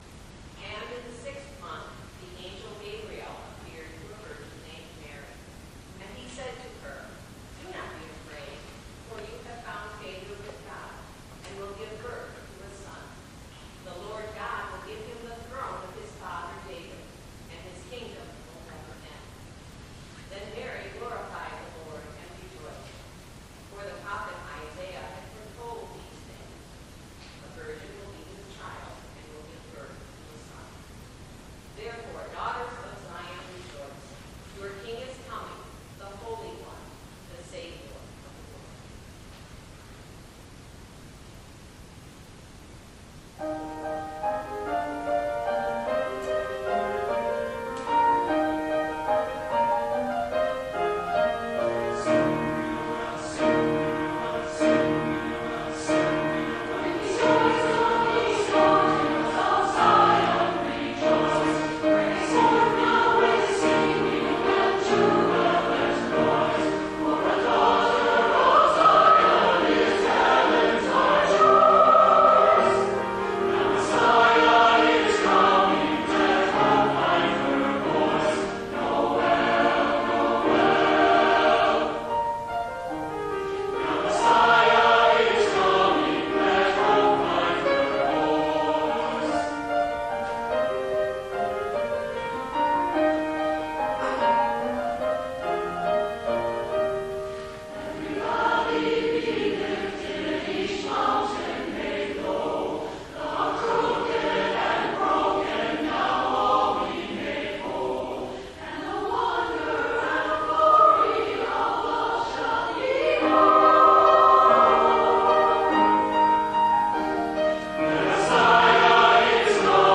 Holiday Concert :: Season of Wonders